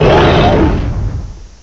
cry_not_pyroar.aif